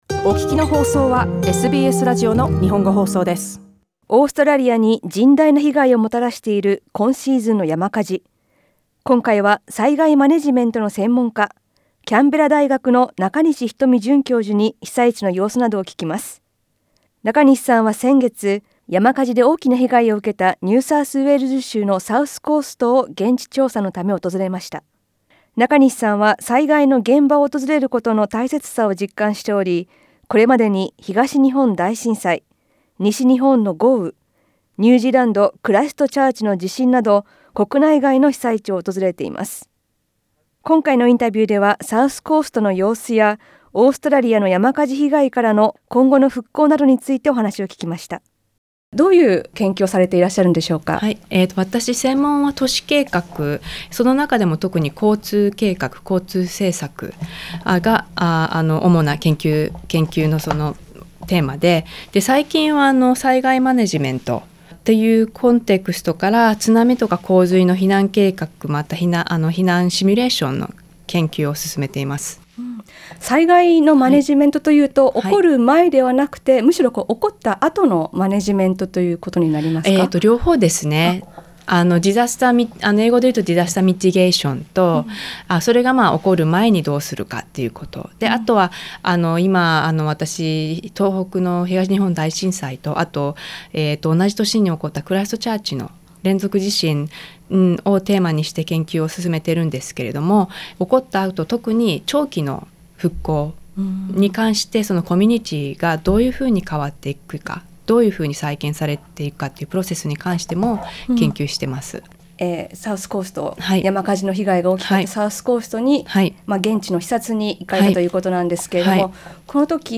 SBSラジオのスタジオでインタビュー収録に臨む